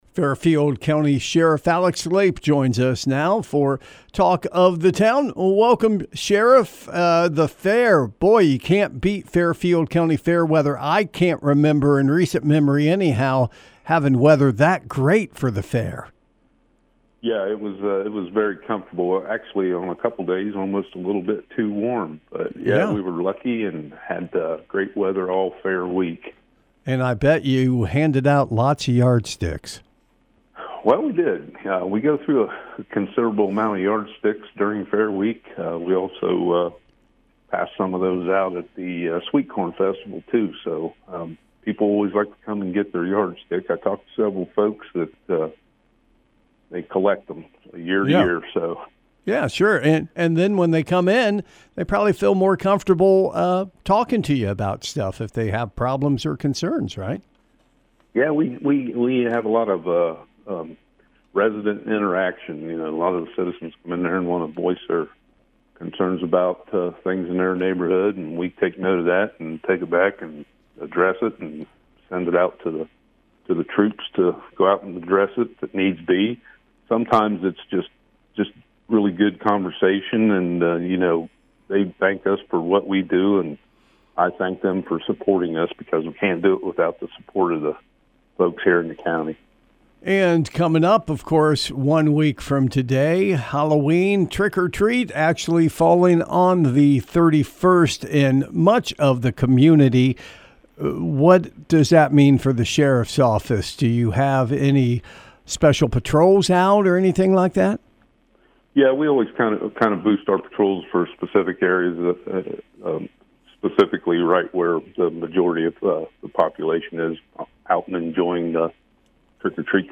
Community Interviews «
interview-sheriff-lape.mp3